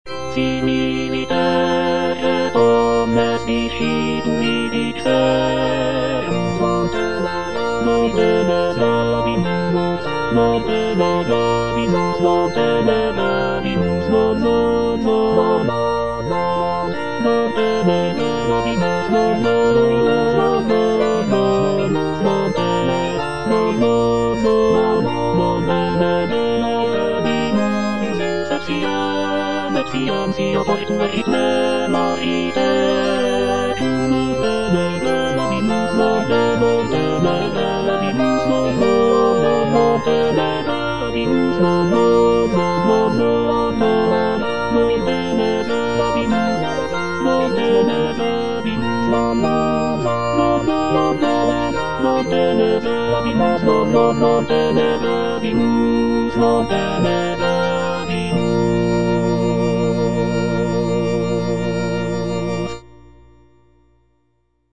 M.A. CHARPENTIER - LE RENIEMENT DE ST. PIERRE Similiter et omnes discipuli dixerunt - Tenor (Emphasised voice and other voices) Ads stop: auto-stop
It is an oratorio based on the biblical story of Saint Peter's denial of Jesus Christ.